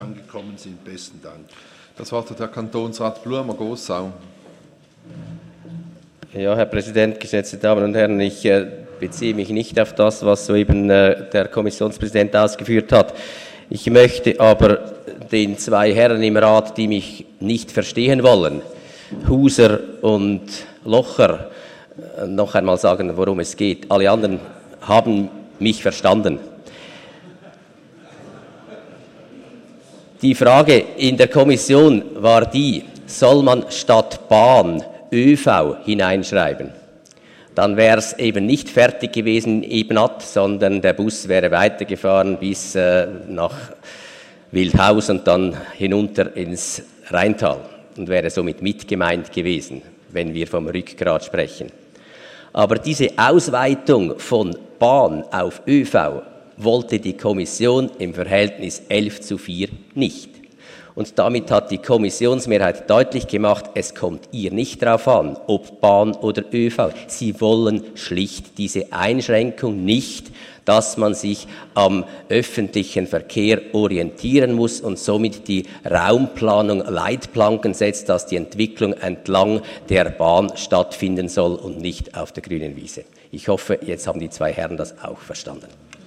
16.9.2015Wortmeldung
Session des Kantonsrates vom 14. bis 16. September 2015